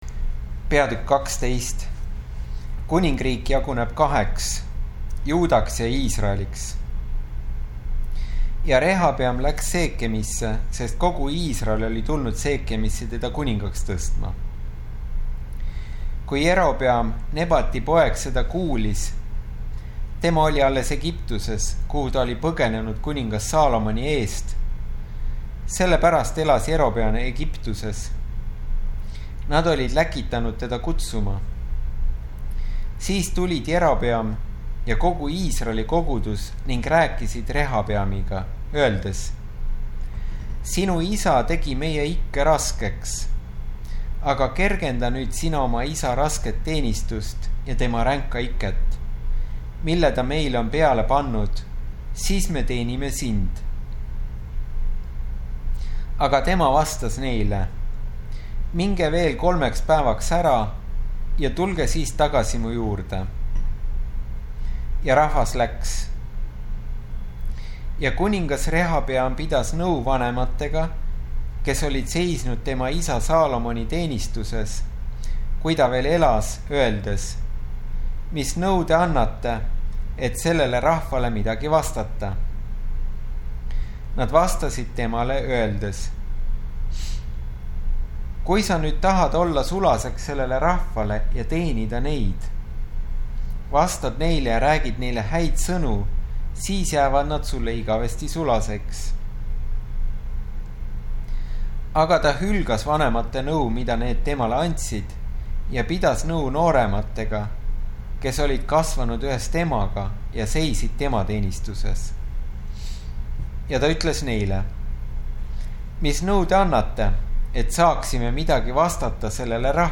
AudioPiibel – 1 Kuningate raamat